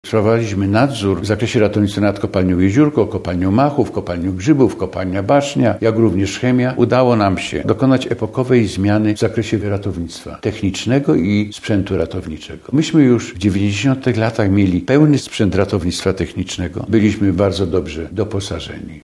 W każdy czwartek, po godzinie 12.00 na antenie Radia Leliwa można wysłuchać rozmów z pracownikami przemysłu siarkowego.